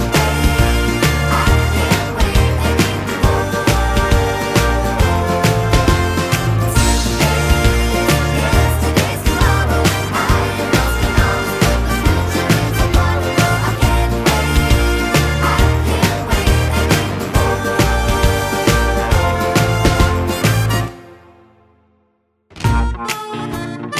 no Backing Vocals Jazz / Swing 3:21 Buy £1.50